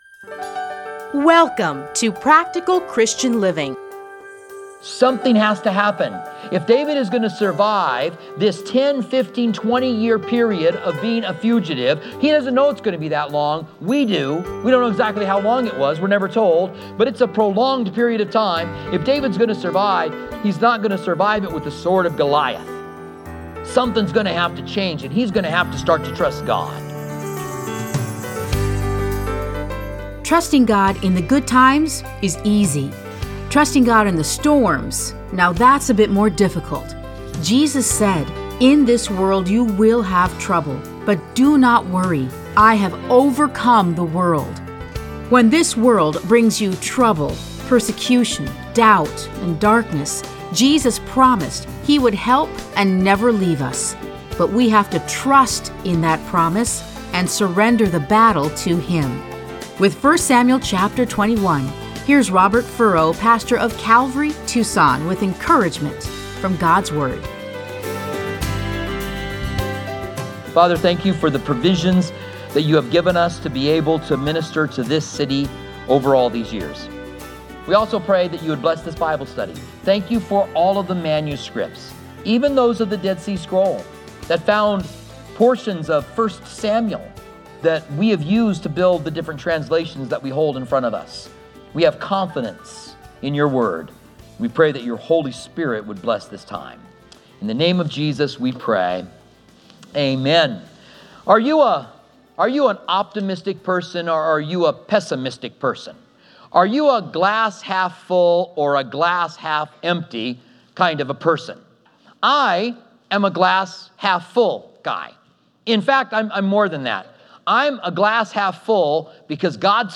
Listen to a teaching from 1 Samuel 21:1-15.